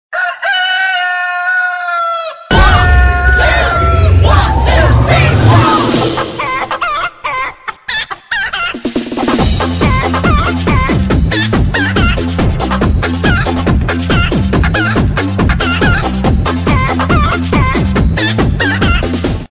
¿Has oído alguna vez a una gallina cantar de esa manera?